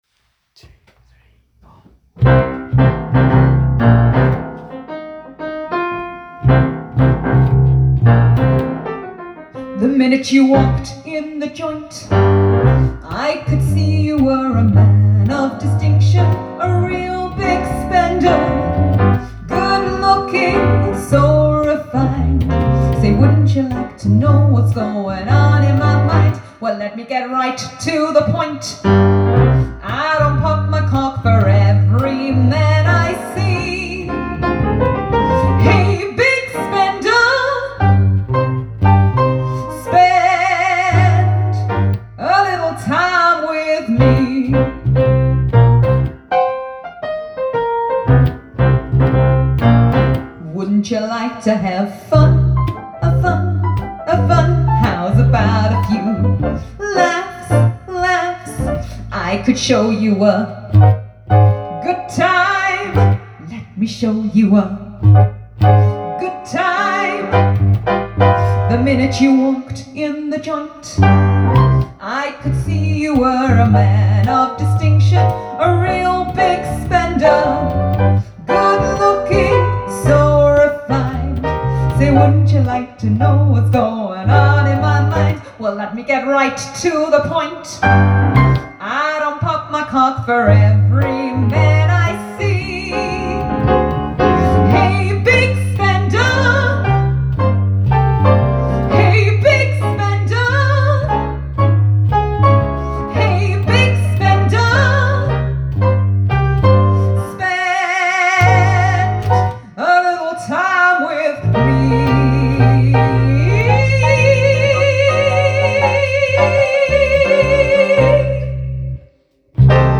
Live rehearsal with Guitarist
Pianist